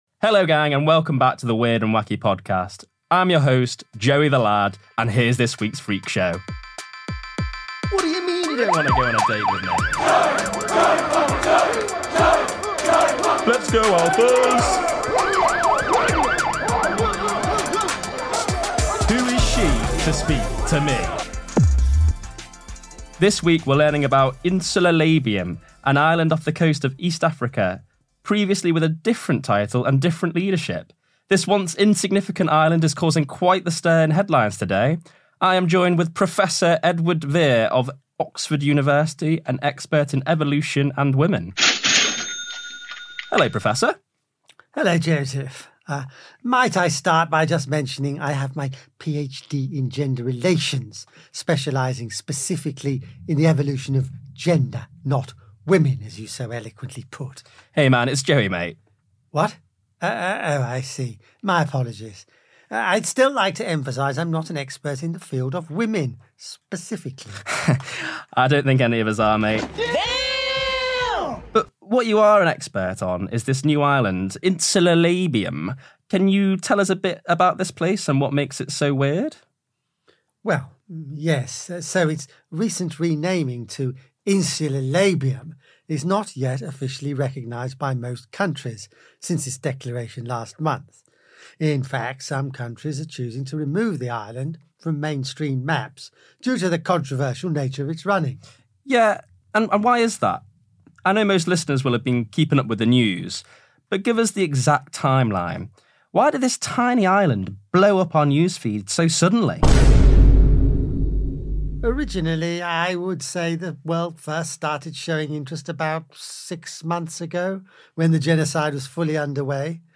Joey the lad was performed by an anonymous participant.